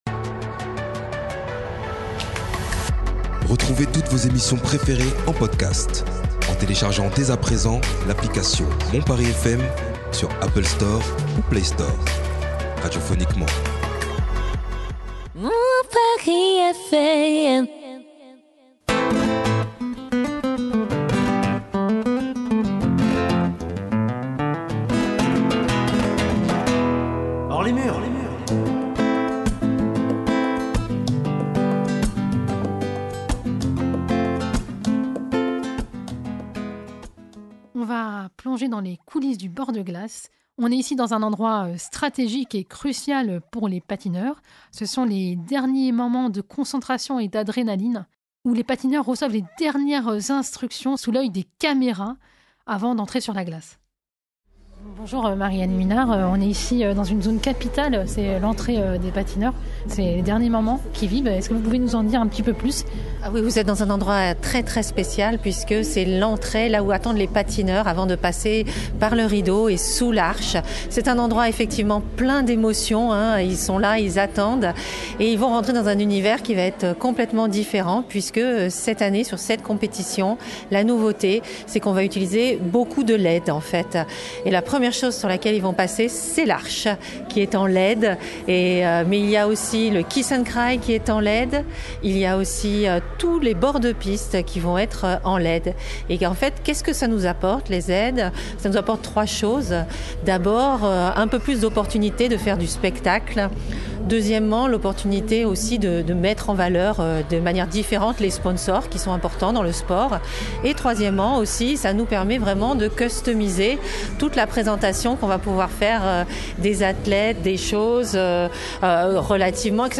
Reportage réalisé à Grenoble le 6 décembre
Reportage